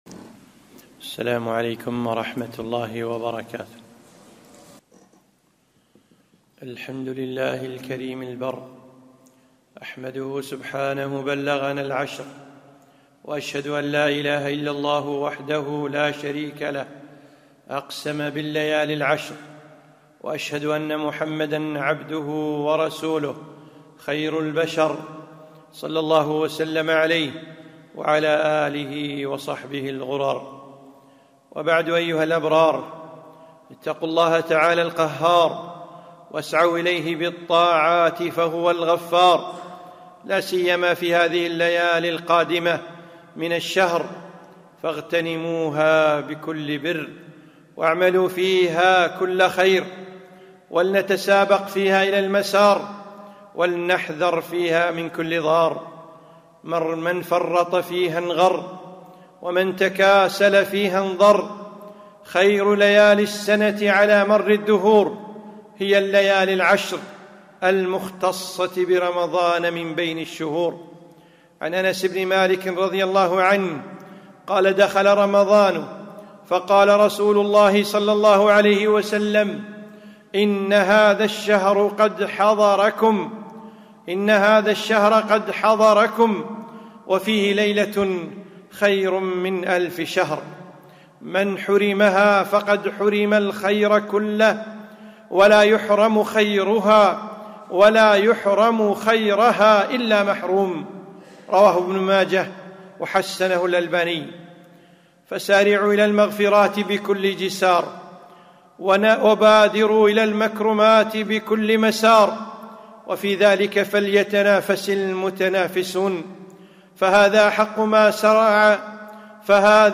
خطبة - رائي القدر